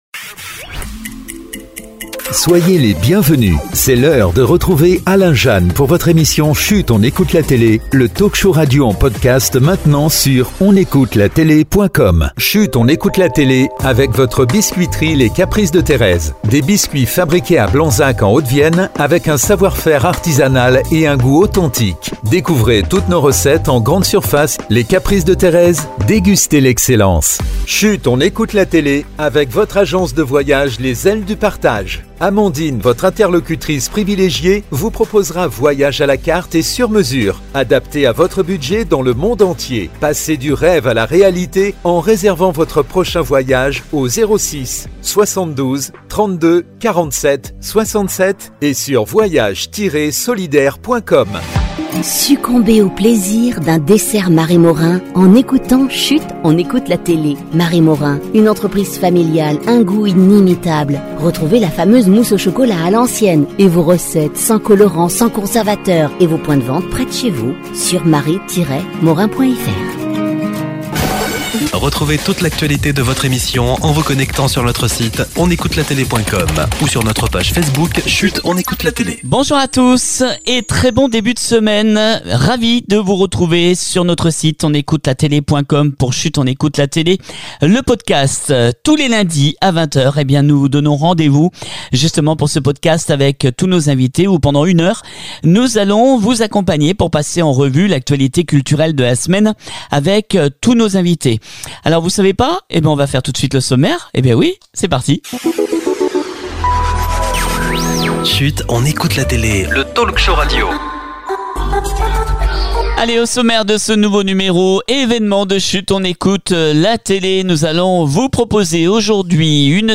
On se retrouve pour une nouvelle émission exceptionnelle de Chut on écoute la télé avec notre coup de coeur cinéma consacré au film “Sur un fil” avec Reda Kateb qui est notre invité